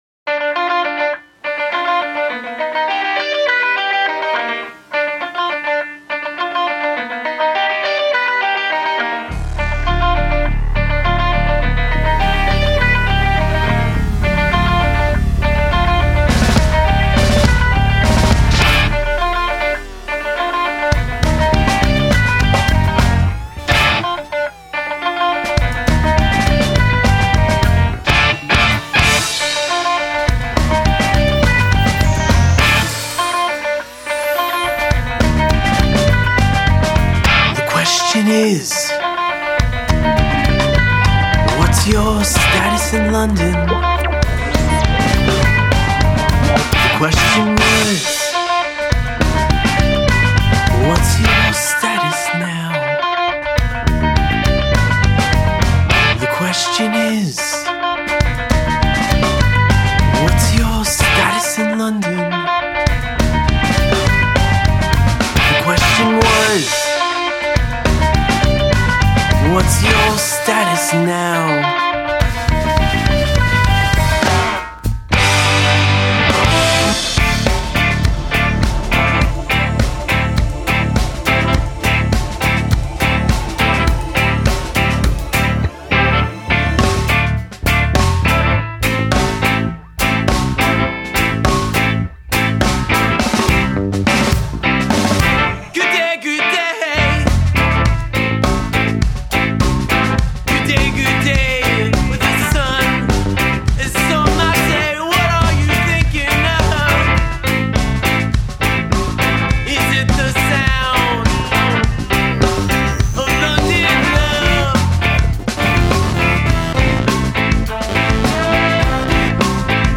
guitar, vocals
bass
drums, vocals